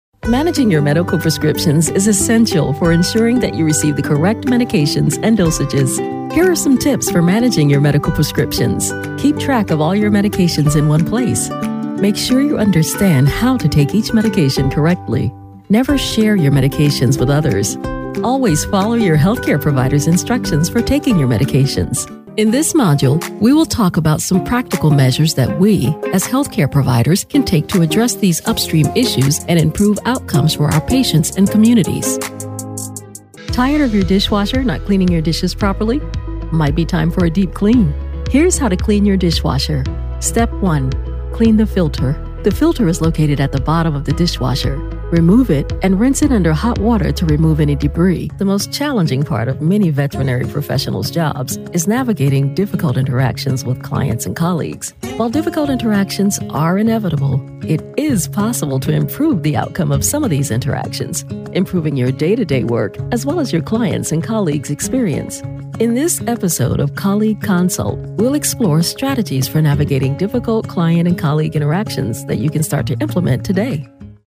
Radio Imaging Demo
American English